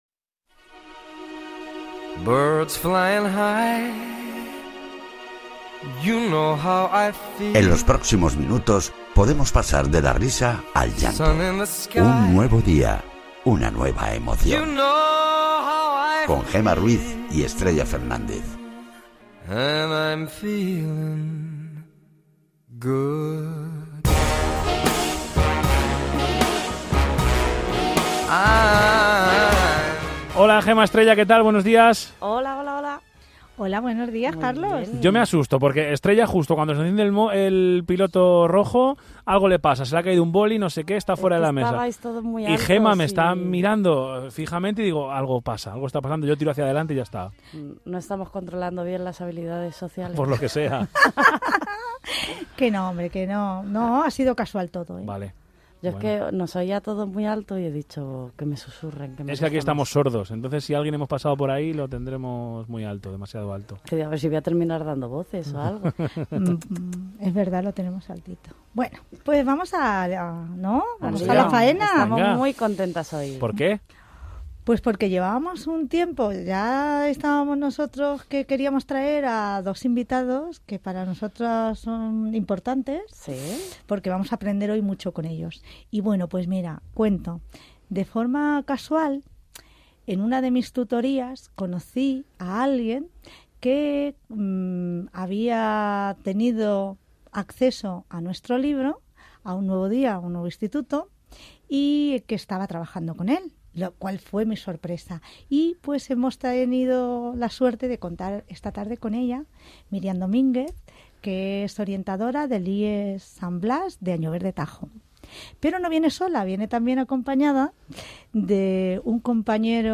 Entrevista cadena SER